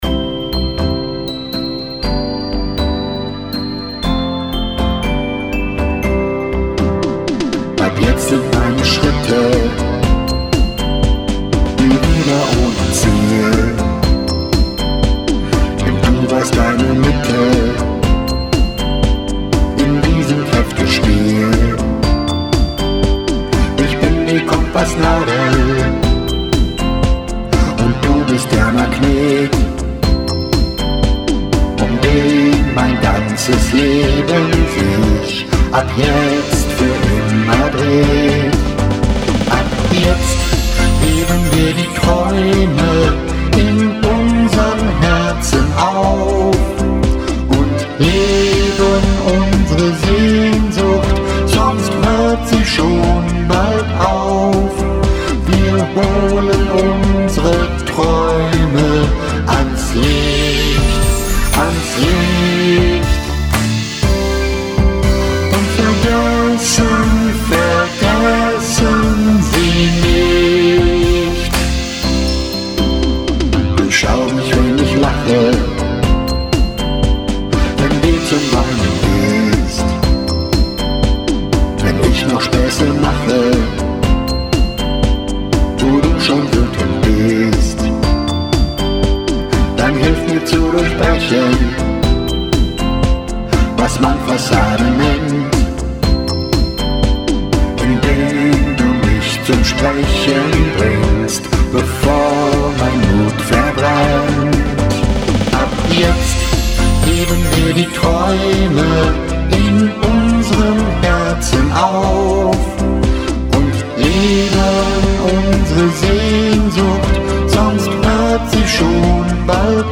Ich habe heute den Demo-Track "besungen". Ich kann gar nicht singen.
Ich kann leider gar nicht singen und habe das jetzt mit Autotune und Vocoder kaschiert, damit man mein "neben-der-Spur-Geröchel" nicht so deutlich hört.